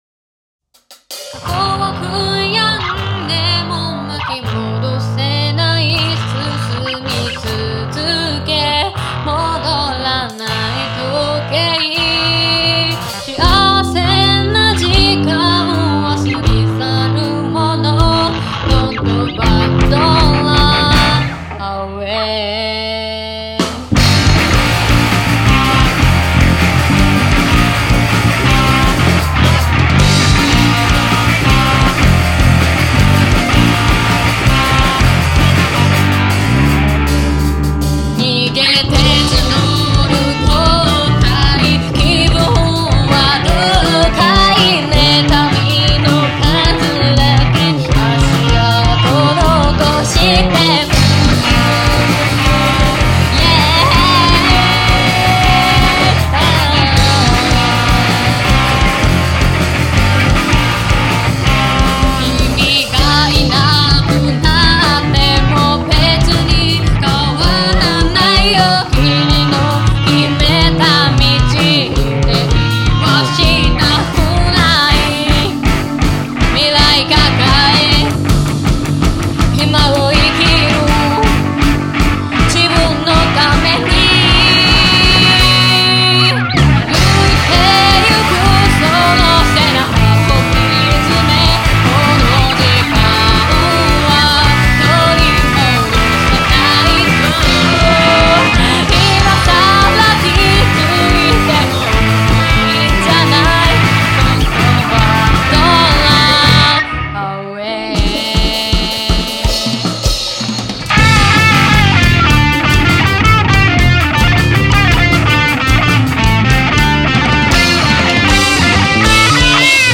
軽音楽部 社会の福祉 オリジナル曲「名も亡き逃亡者たちへ」 試聴開始！